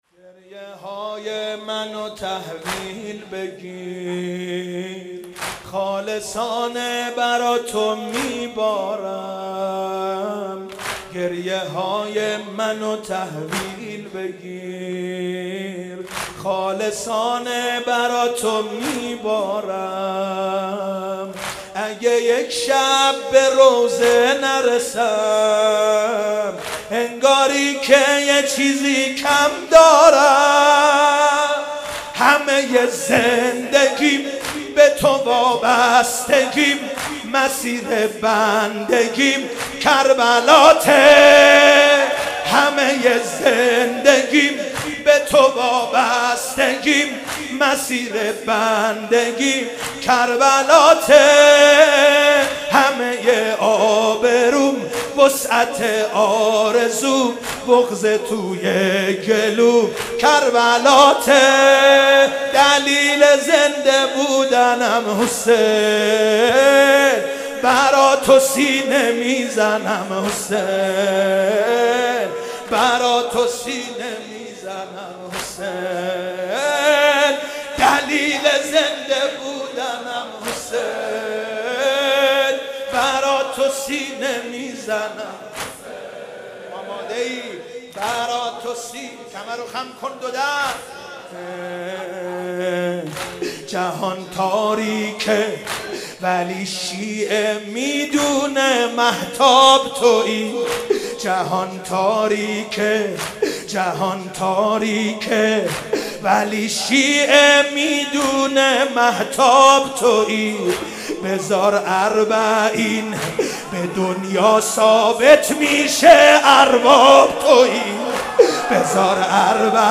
واحد ، سال 94،جدید